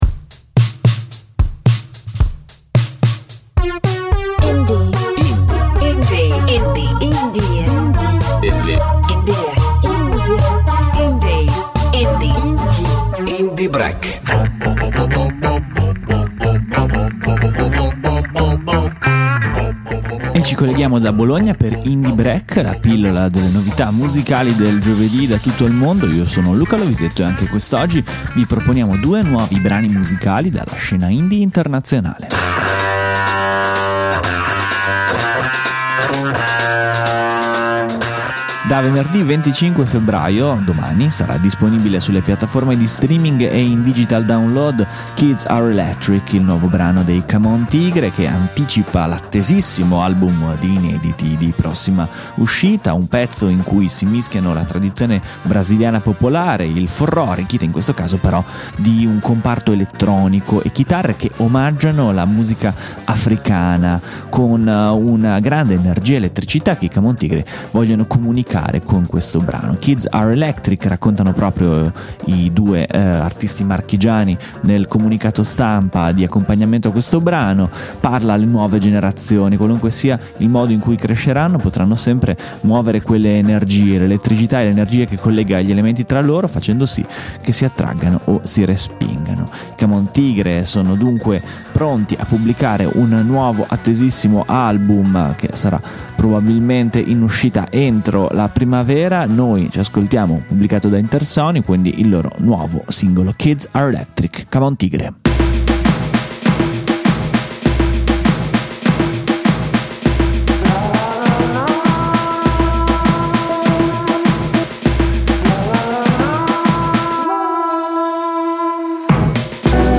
Puntata tutta dedicata alle sonorità world e etno-folk, con due anticipazioni di altrettanti ottimi album in uscita nei prossimi mesi